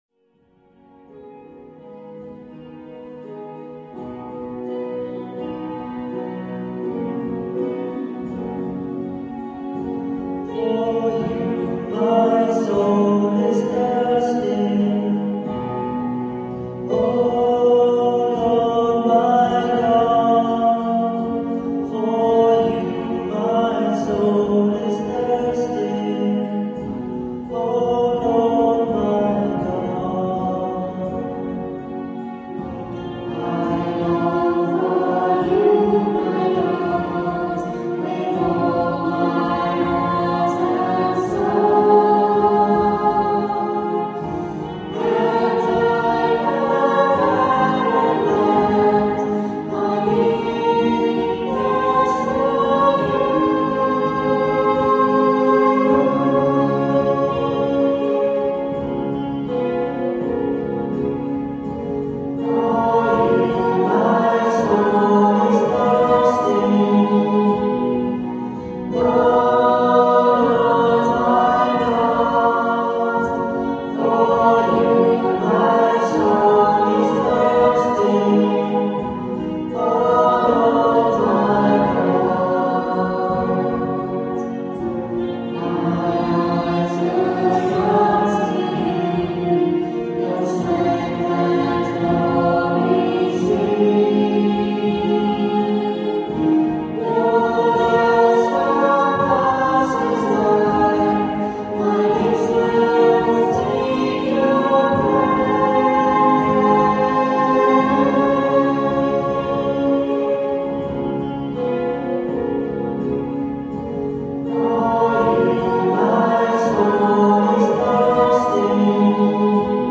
Recorded at Wednesday night rehearsal, 8pm, 24th February 1999 in digital stereo on minidisc.